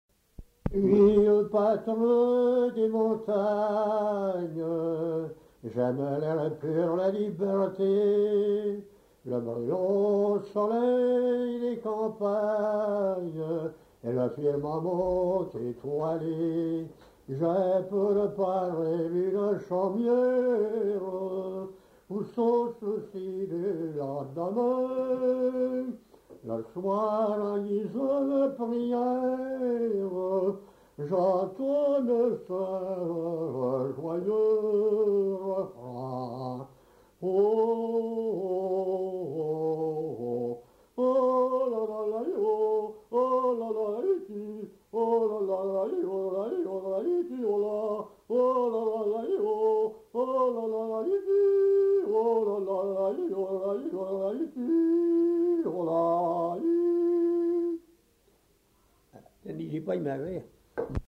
Lieu : Cazaux-Savès
Genre : chant
Effectif : 1
Type de voix : voix d'homme
Production du son : chanté ; effet de voix